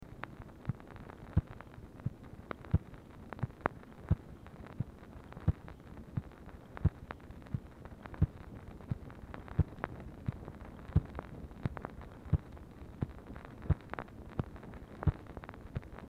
Telephone conversation # 2250, sound recording, MACHINE NOISE, 2/8/1964, time unknown | Discover LBJ
BLANK NON-GROOVED SEGMENT ON ORIGINAL DICTABELT
Format Dictation belt